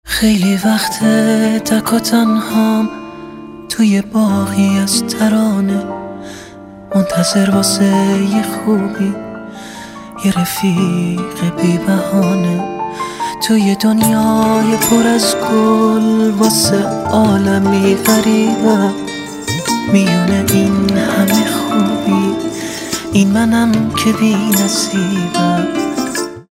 رینگتون احساسی و باکلام